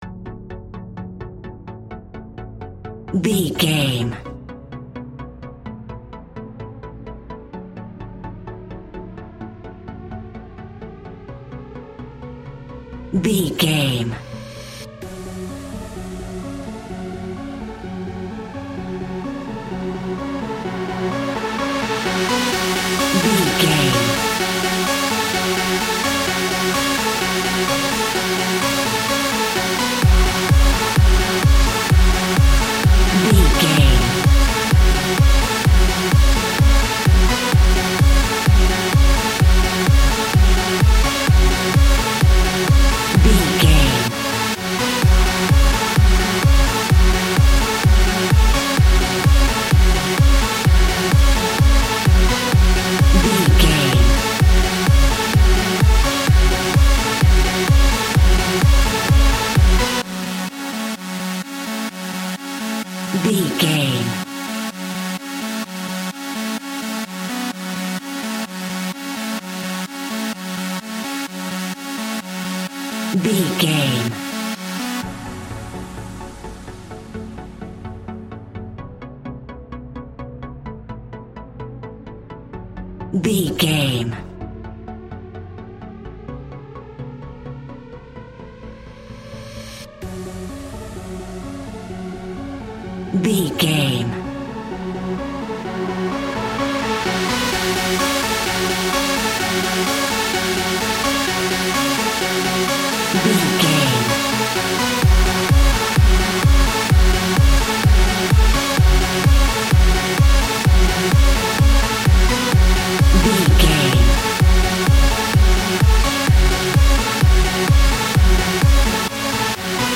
Clubbing Trance Music.
Fast paced
Aeolian/Minor
F#
groovy
uplifting
driving
energetic
repetitive
bouncy
synthesiser
drum machine
trance
acid house
electronic
synth leads
synth bass